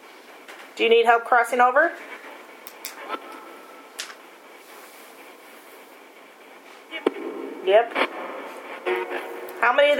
When doing the Heaven’s Gate (Spirit box) spirits were asked how many needed to cross over and it appeared to say 10.
EVP’s Captured during Paranormal Investigation
Heaven’s Gate Session
Dearborn-HTS-Heavens-Gate-do-you-need-help-crossing-over-YEP.wav